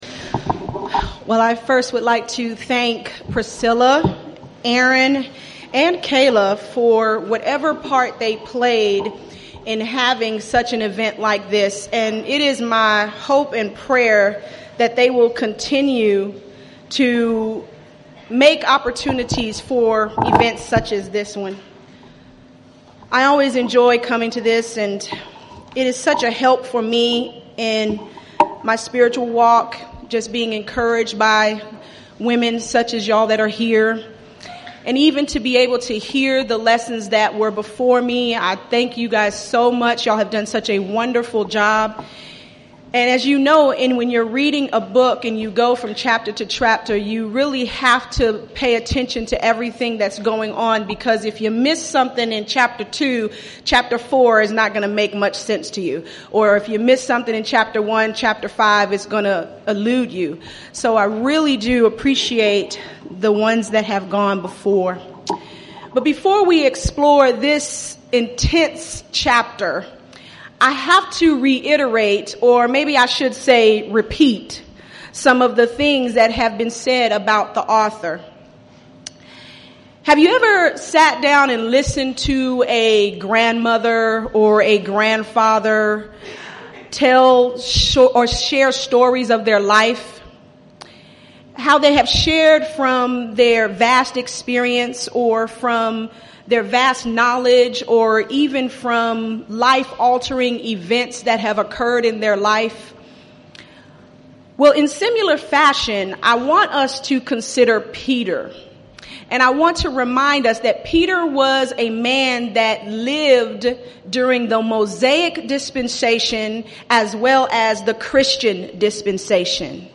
Event: 3rd Annual Texas Ladies in Christ Retreat Theme/Title: Studies in I Peter